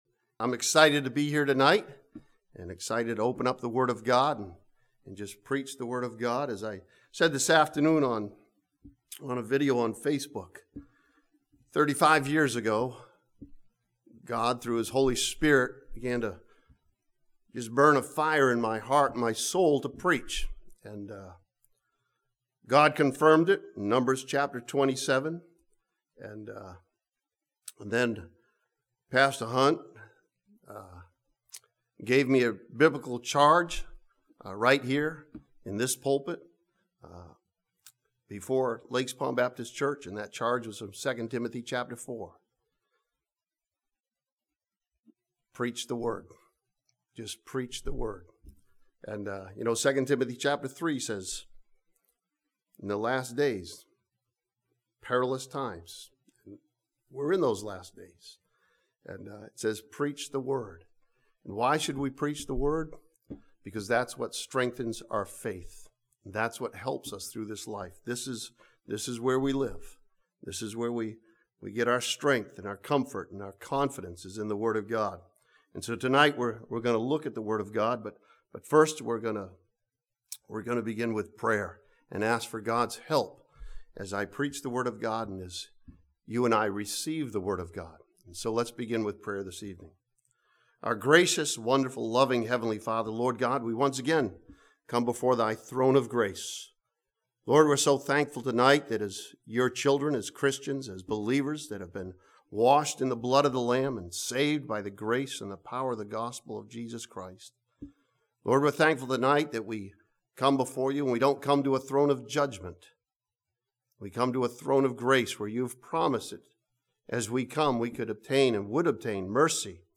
This sermon from James chapter 4 challenges believers to remember that God is not broke and to replace fear with faith.